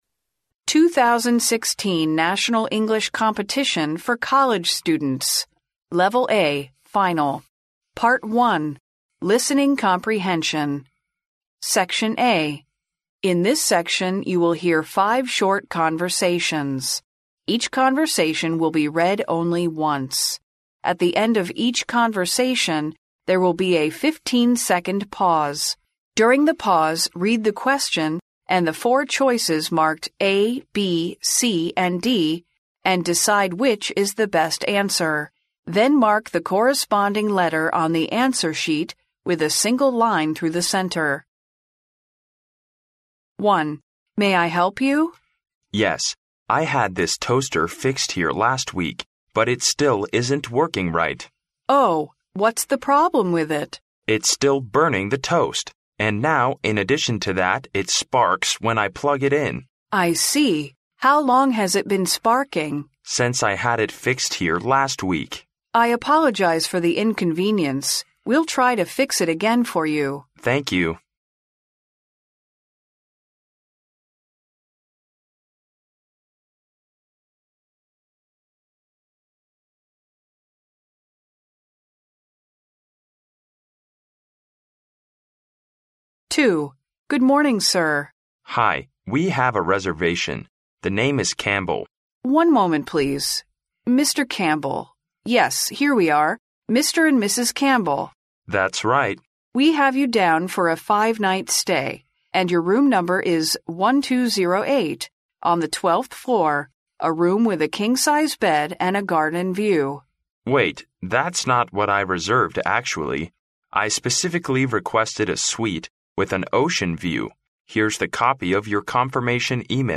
In this section, you will hear five short conversations. Each conversation will be read only once. At the end of each conversation, there will be a fifteen-second pause.